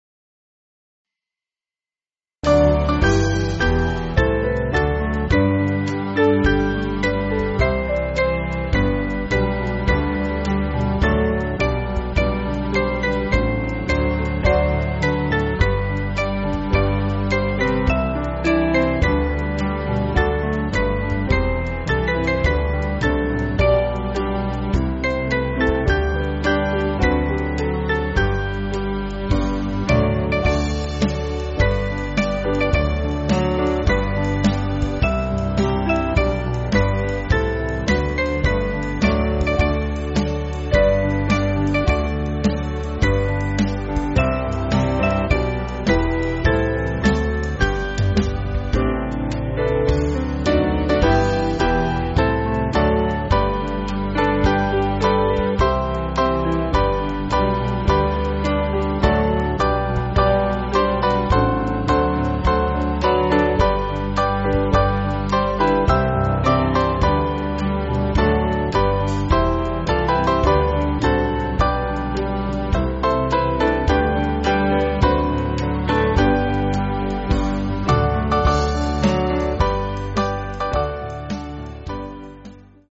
Small Band
(CM)   4/G